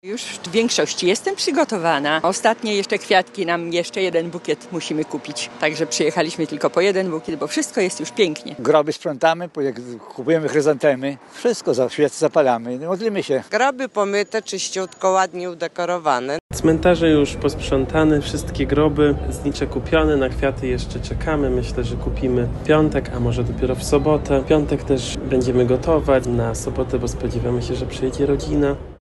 Sprawdziliśmy, jak wyglądają przygotowania do Wszystkich Świętych na lubelskiej nekropolii przy Majdanku:
SONDA